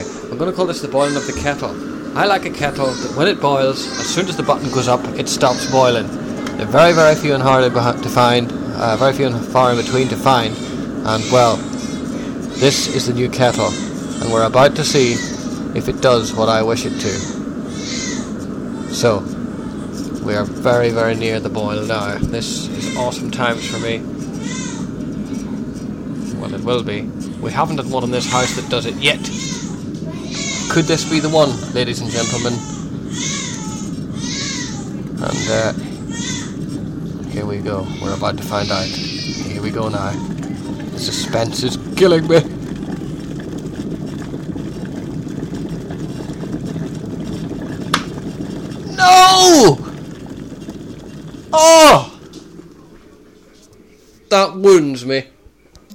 The boiling a kettle